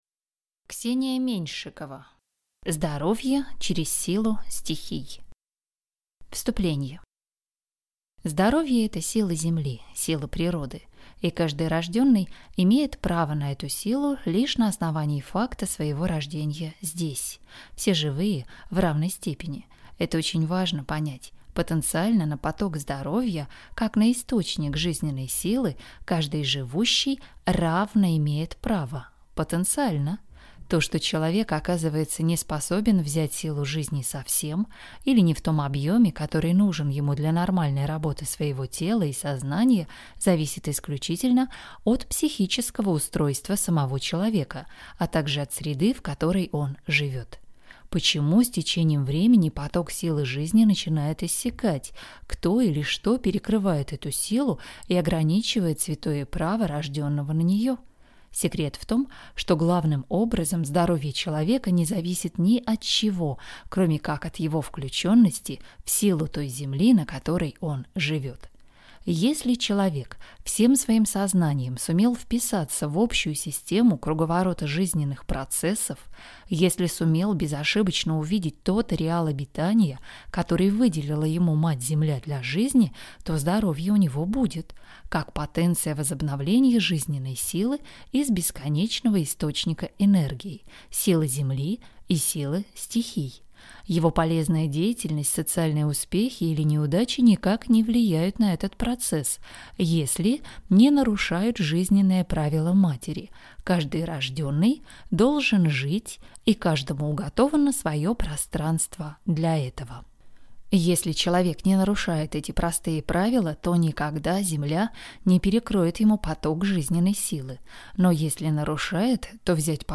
Аудиокнига Здоровье через силу стихий | Библиотека аудиокниг
Прослушать и бесплатно скачать фрагмент аудиокниги